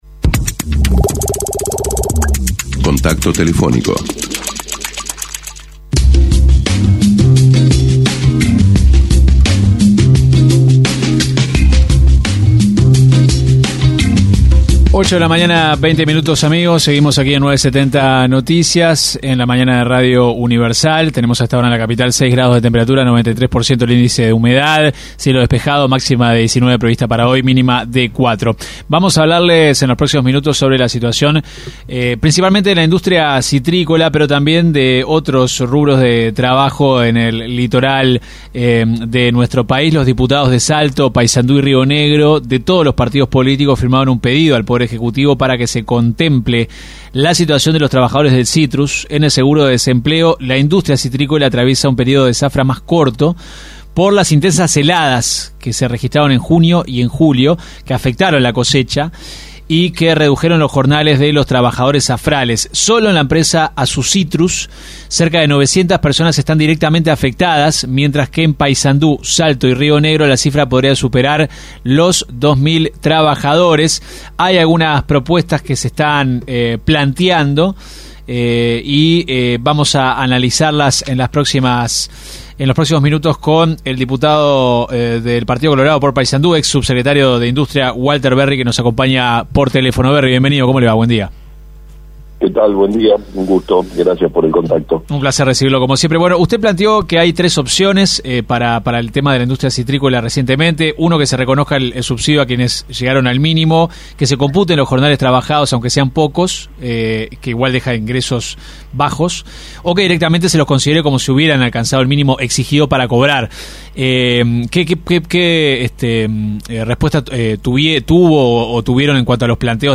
El diputado colorado Walter Verri, fue consultado por 970 Noticias sobre su visión respecto a la decisión del Gobierno de bajar el porcentaje de descuento del Imesi en los combustibles en la frontera con Argentina.